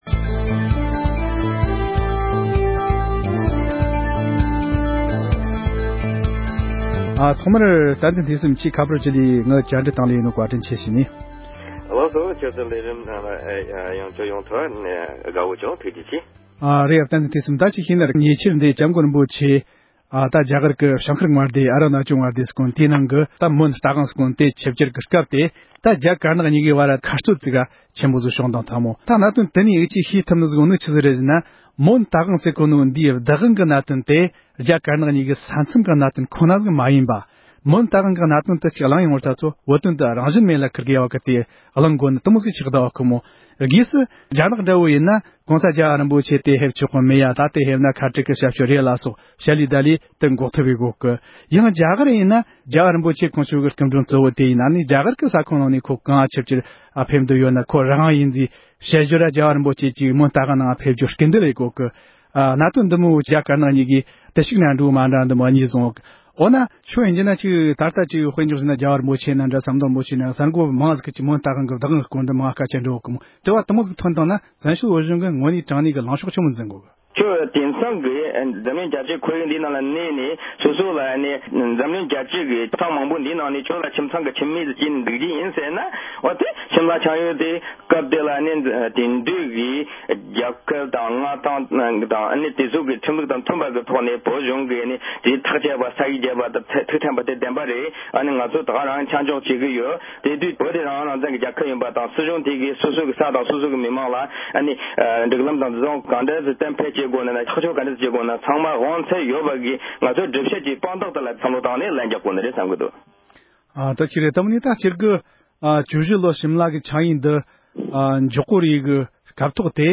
རྒྱ་གར་གྱི་བྱང་ཤར་དུ་ཡོད་པའི་མོན་རྟ་དབང་ས་ཁུལ་གྱི་བྱུང་རིམ་དང་ལོ་རྒྱུས་ཐད་བགྲོ་གླེང་ཞུས་པའི་ལེ་ཚན་གསུམ་པ།